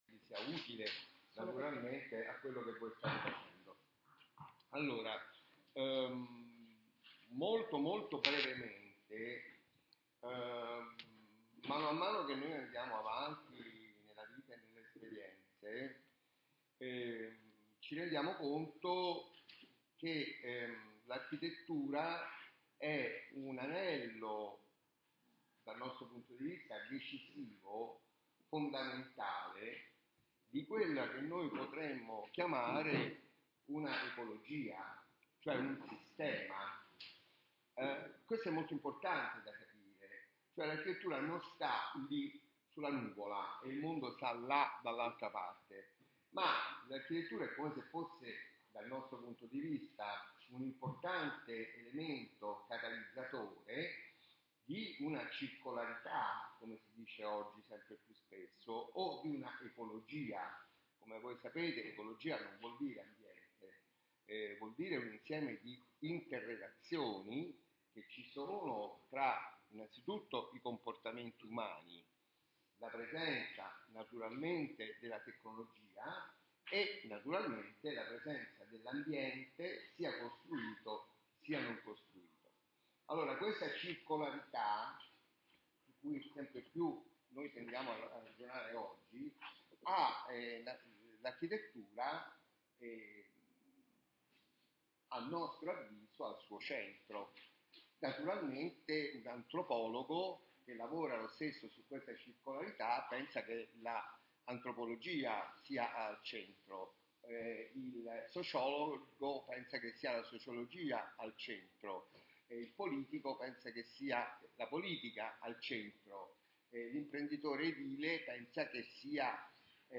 Lezione su Louis Sauer alla Sapienza di Roma 2026